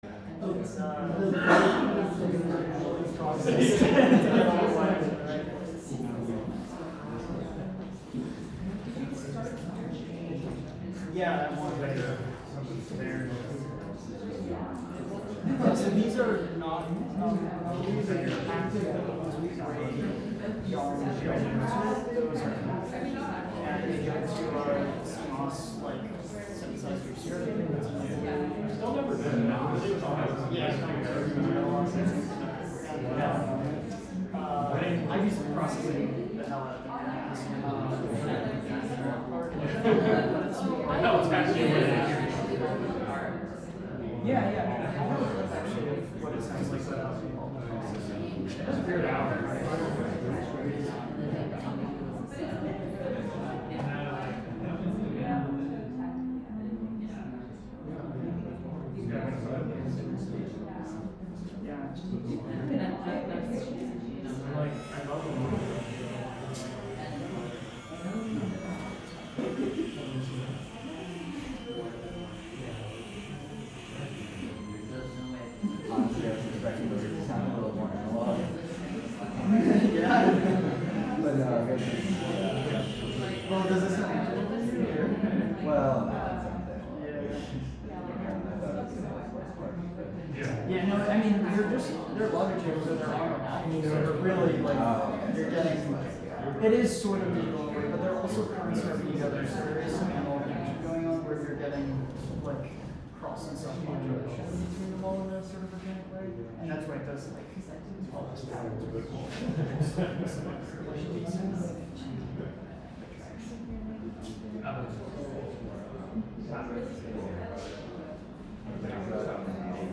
live from the Fridman Gallery, NYC